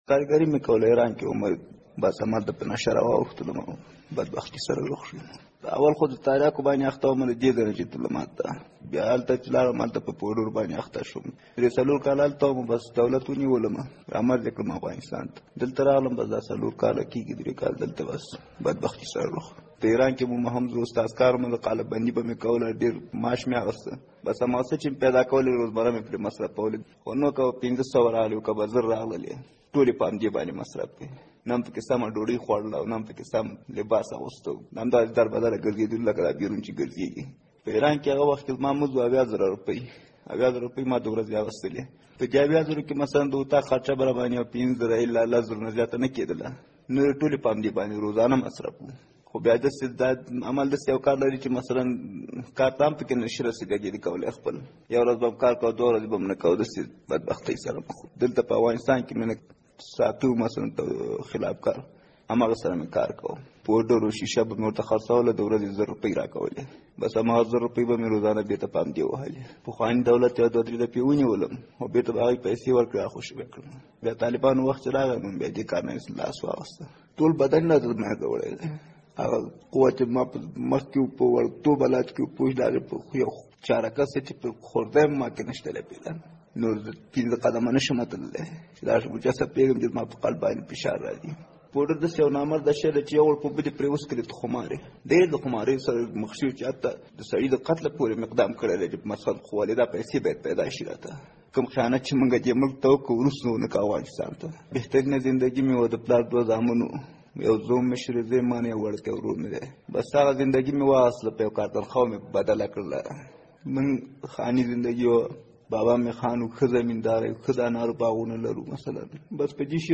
په نشو معتاد کس